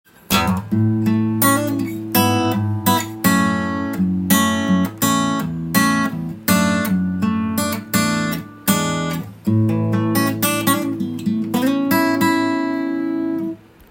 音の方は、古風な昔の音がしますのでドンシャリというより
ソフトで爽やかな感じです！
弦高が丁度良いので指弾きをしても、滑らかに弾ける雰囲気です。